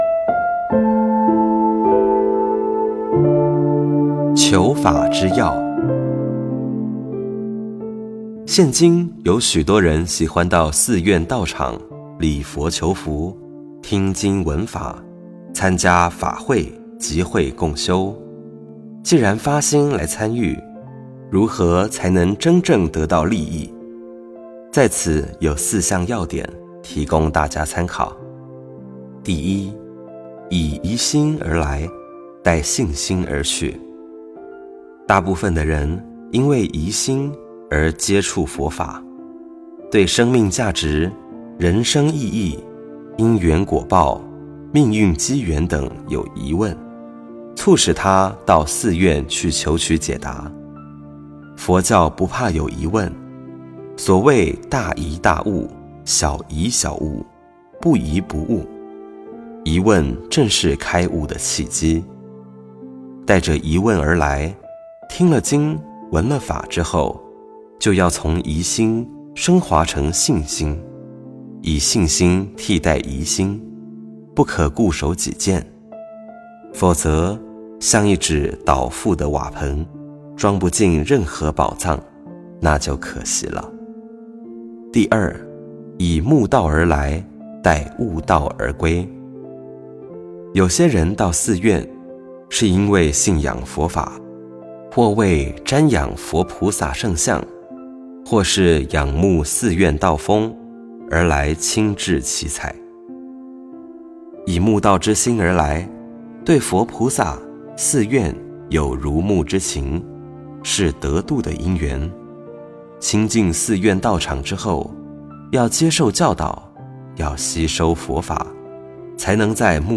佛音 冥想 佛教音乐 返回列表 上一篇： 14.